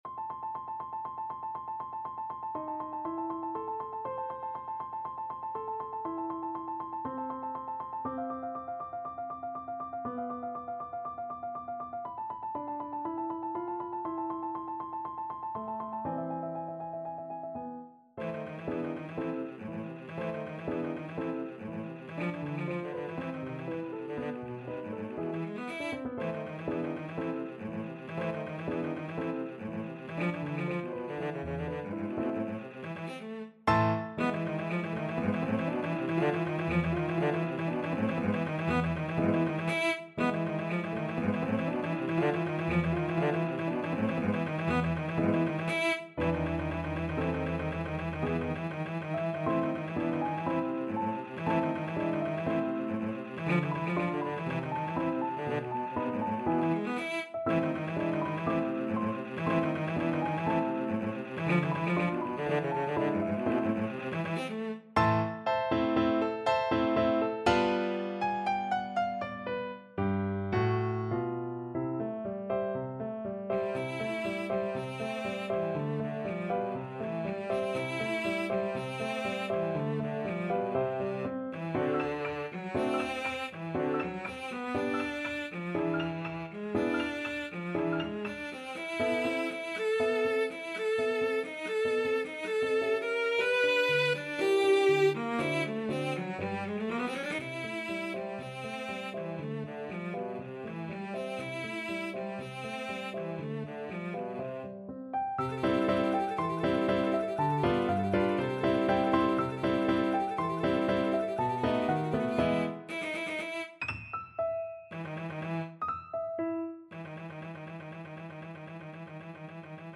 2/4 (View more 2/4 Music)
= 120 Allegro molto vivace (View more music marked Allegro)
Classical (View more Classical Cello Music)